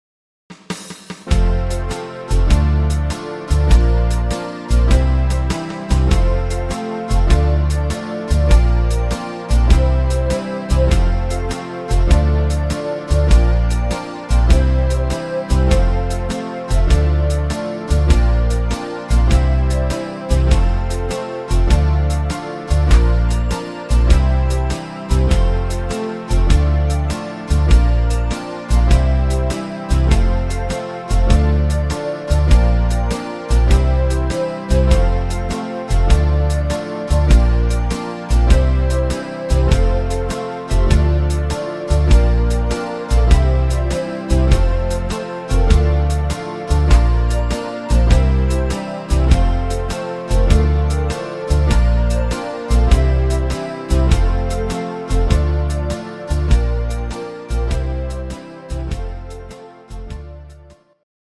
schönes Kinderlied